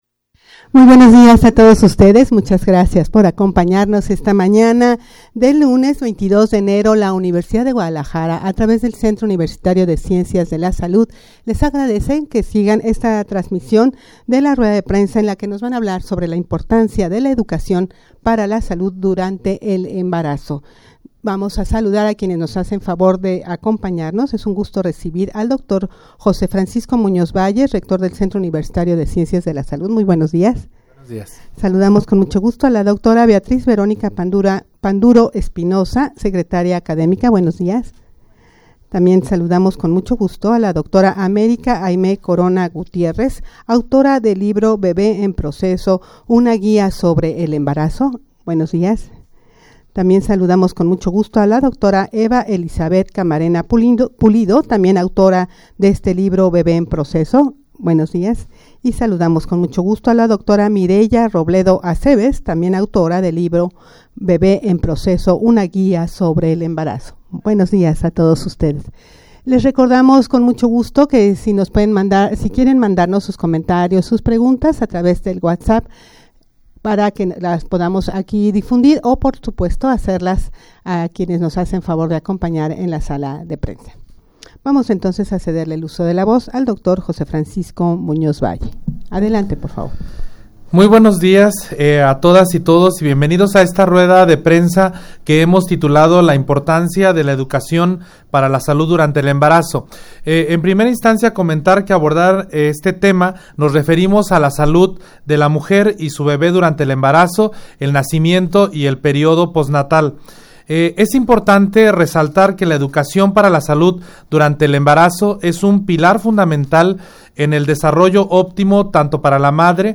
Audio de la Rueda de Prensa
rueda-de-prensa-la-importancia-de-la-educacion-para-la-salud-durante-el-embarazo.mp3